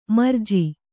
♪ marji